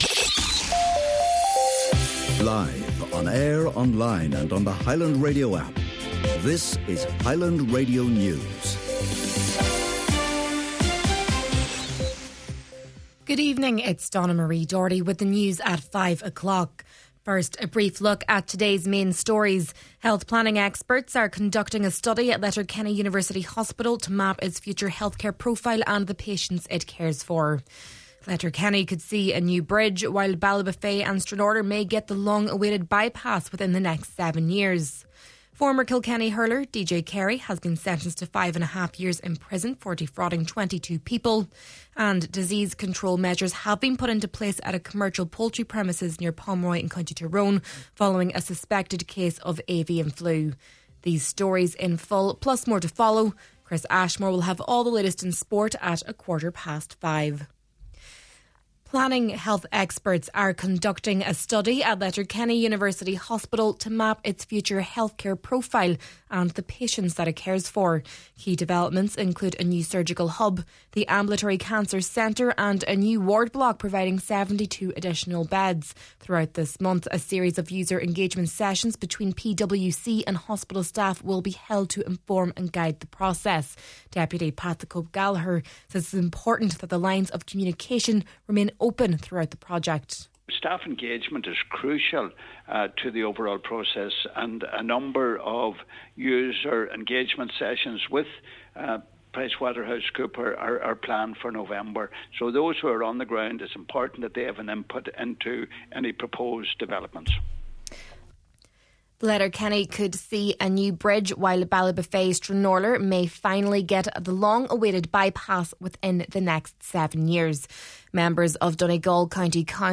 Main Evening News, Sport and Obituaries – Monday, November 3rd